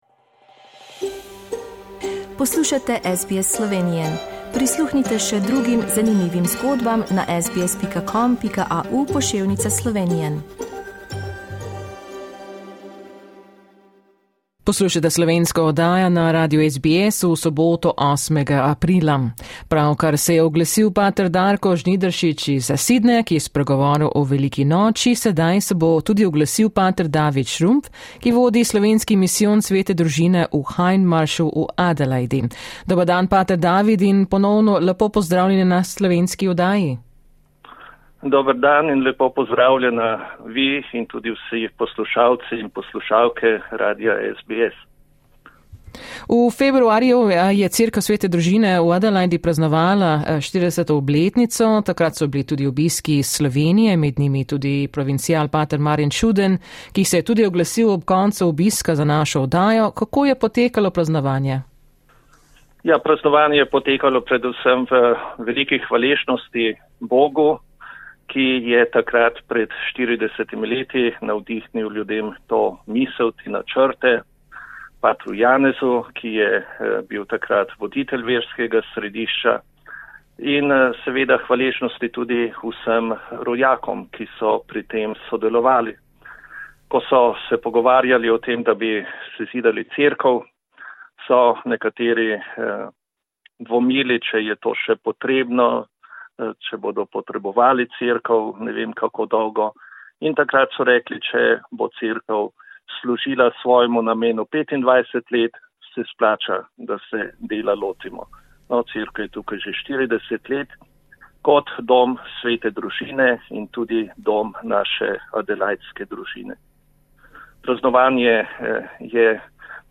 Velikonočno sporočilo iz Adelaide